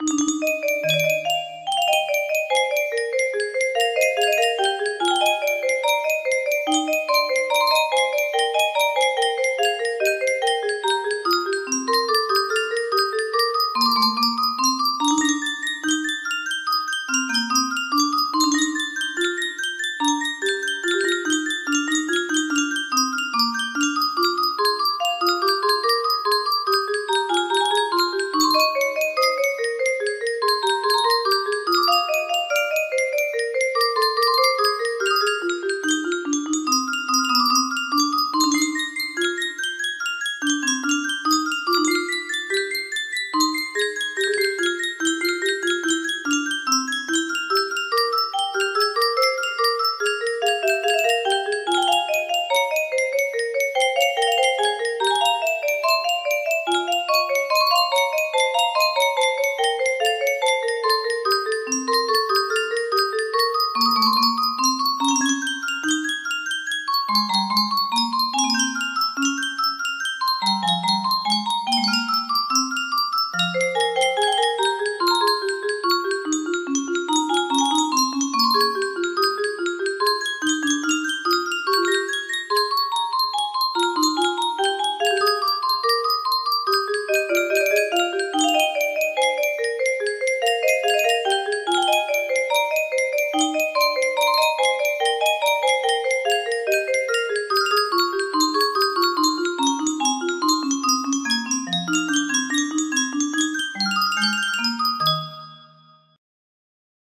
Bach Invention No. 5 in E-flat Major BWV 776 music box melody
Full range 60